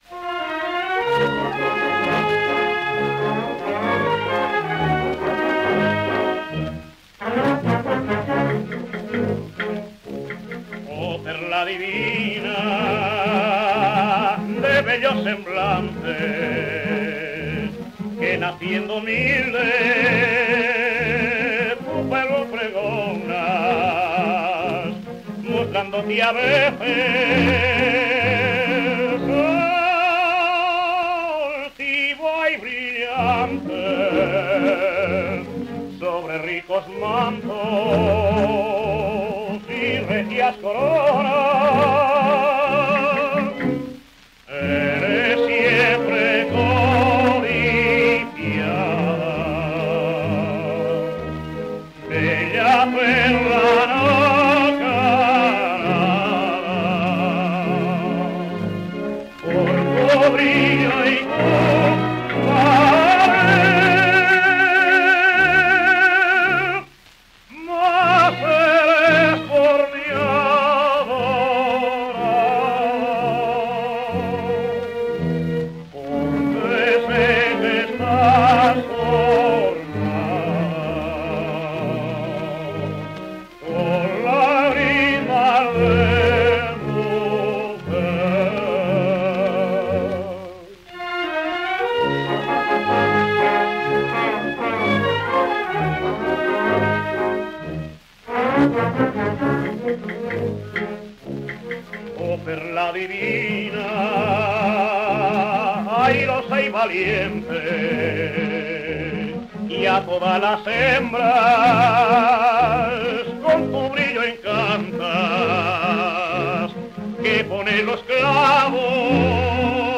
[Blues]
Tenor
Gramófono.